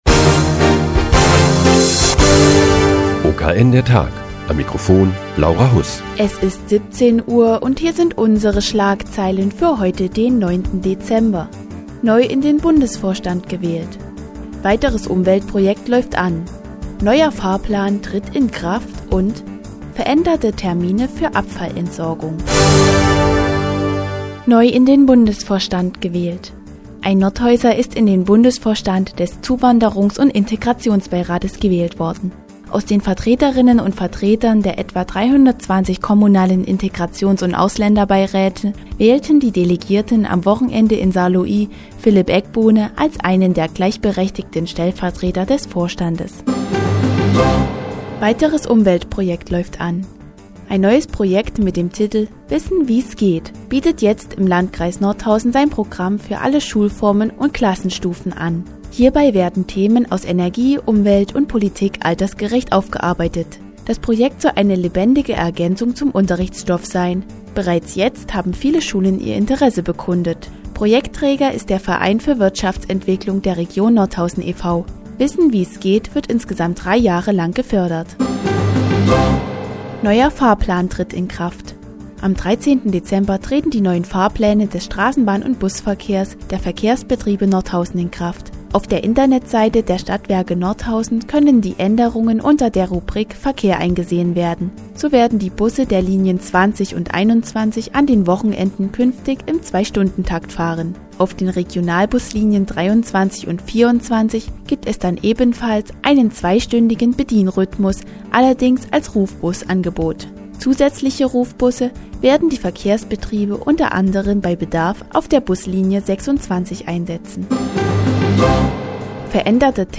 Die tägliche Nachrichtensendung des OKN ist nun auch in der nnz zu hören. Heute geht es um das Projekt "Wissen wie's geht" und den neuen Fahrplan des Straßenbahn- und Busverkehrs.